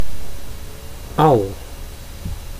Ääntäminen
IPA: /ɑl/